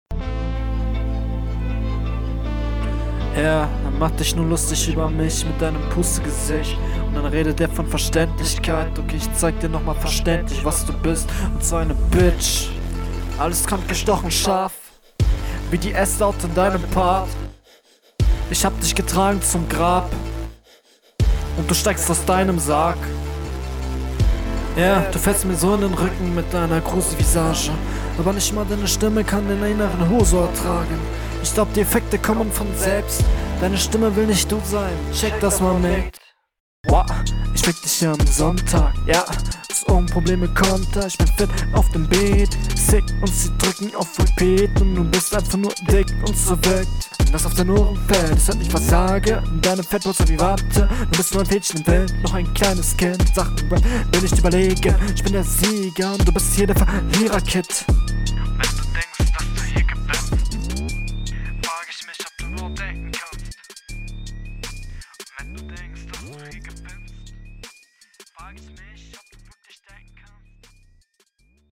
Flowlich leider durcheinander und undeutlich